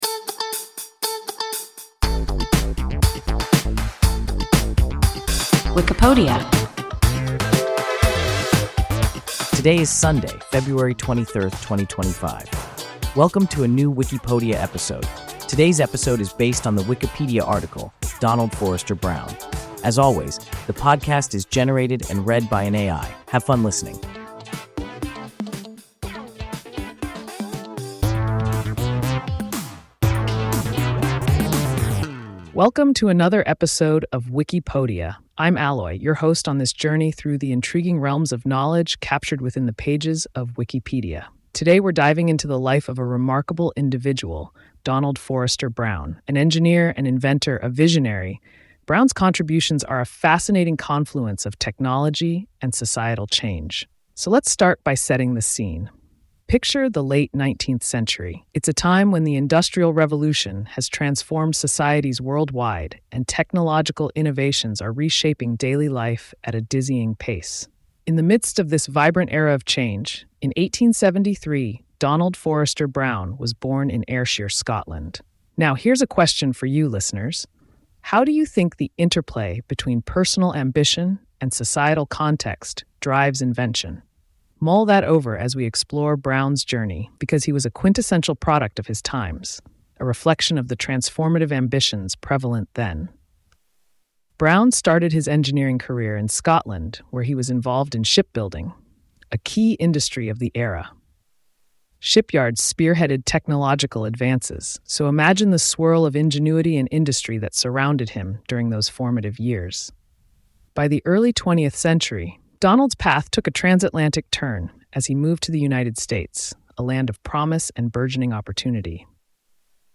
Donald Forrester Brown – WIKIPODIA – ein KI Podcast